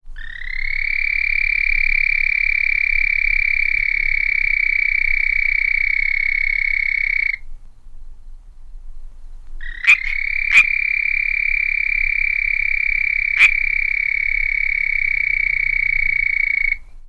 The call of the Red-spotted Toad is a prolonged loud high-pitched musical trill, lasting up to 10 seconds, which is produced at night .
Sound   This is a 17 second recording of a series of two calls from the previous toad. Several calls of Pseudacris cadaverina - California Treefrog are heard in the second half.